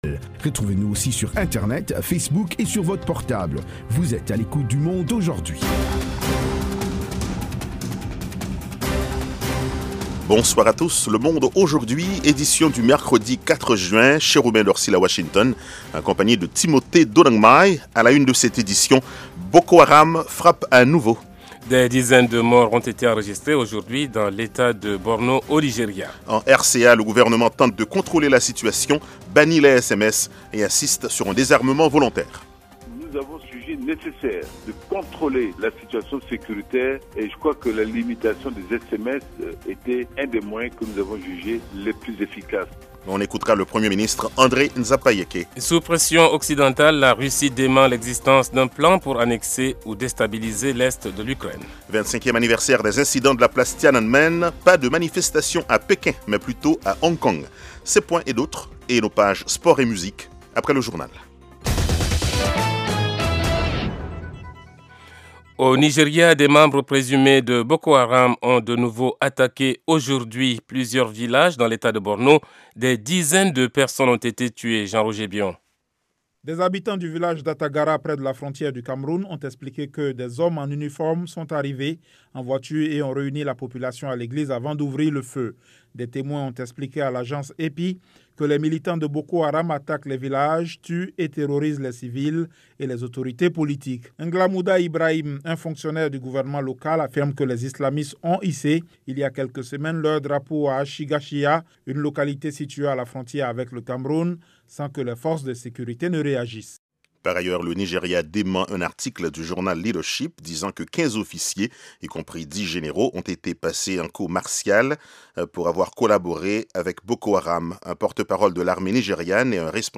Interviews, reportages de nos envoyés spéciaux et de nos correspondants, dossiers, débats avec les principaux acteurs de la vie politique et de la société civile. Aujourd'hui l'Afrique Centrale vous offre du lundi au vendredi une synthèse des principaux développementsdans la région.